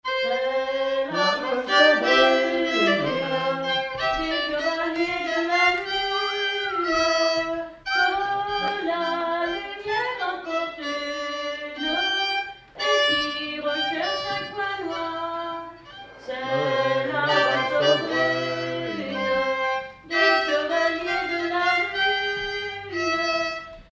Villard-sur-Doron
danse : valse
circonstance : bal, dancerie
Pièce musicale inédite